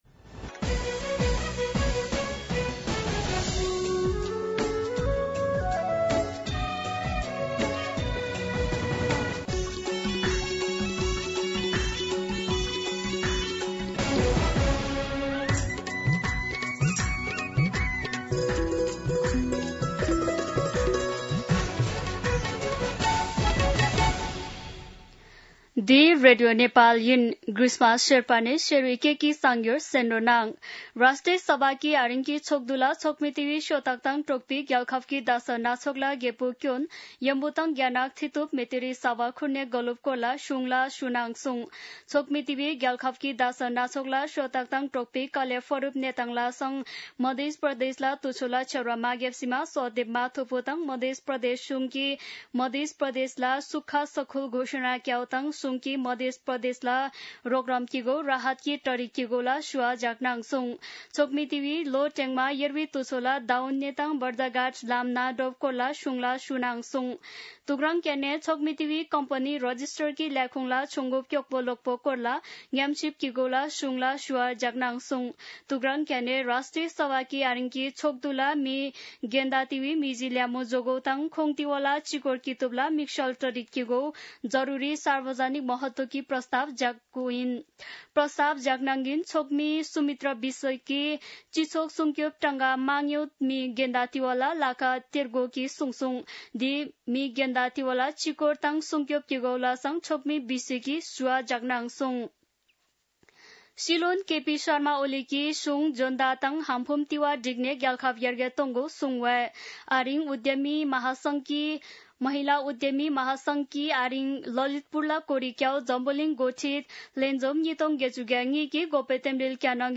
An online outlet of Nepal's national radio broadcaster
शेर्पा भाषाको समाचार : २७ असार , २०८२
Sherpa-News.mp3